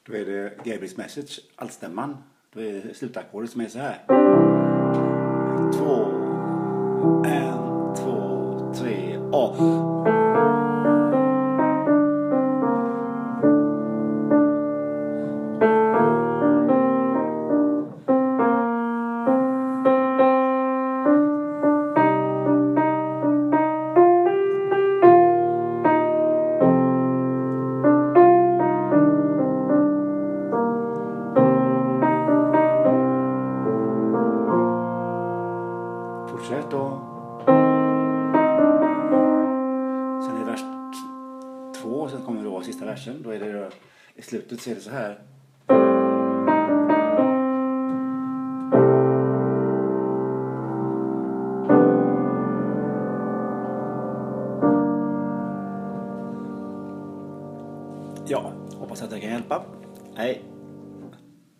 Gabriels Message Alt.mp3